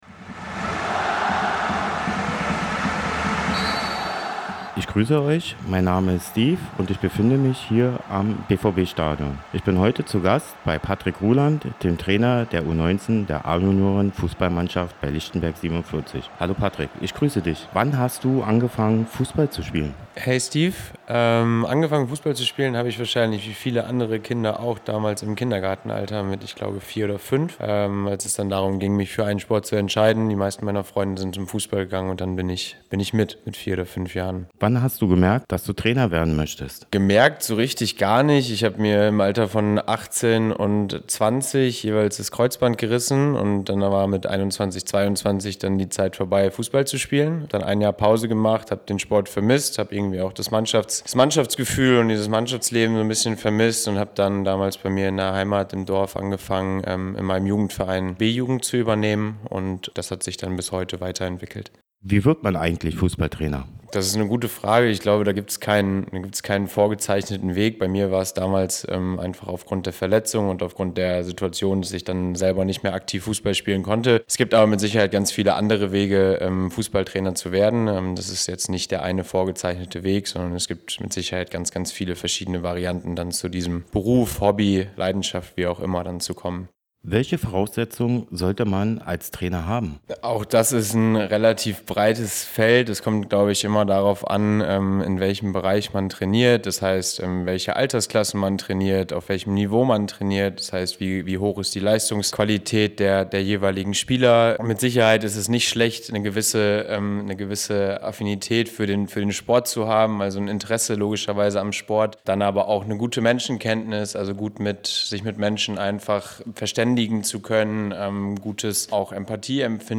Interview mit einem Amateur Fußballtrainer